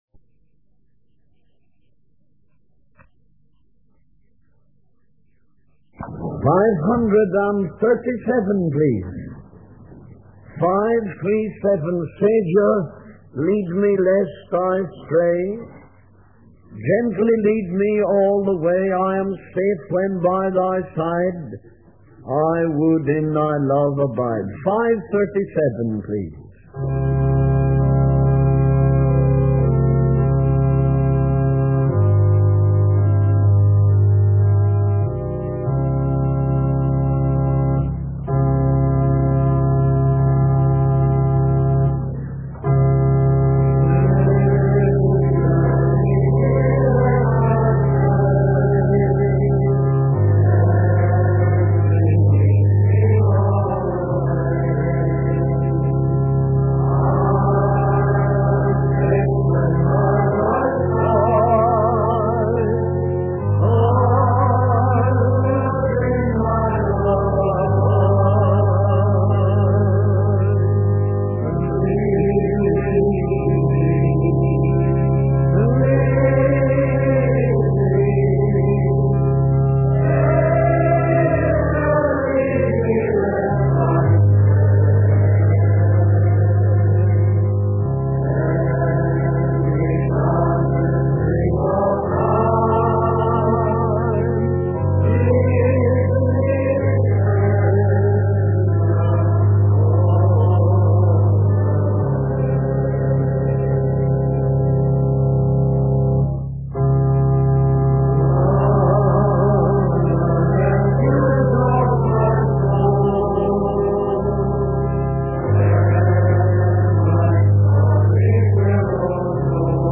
In this sermon, the preacher emphasizes the importance of remembering the glorious gospel that we have received and holding fast to it. He warns that there are many dedicated churchgoers who do not truly know Christ and will be caught off guard when He returns. The preacher urges the congregation to be watchful and strengthen the things that are ready to die.